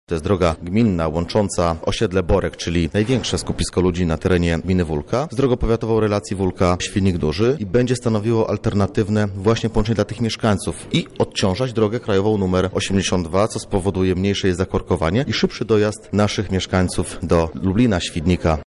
Na co środki zamierza przeznaczyć gmina Wólka tłumaczy jej wójt – Edwin Gortat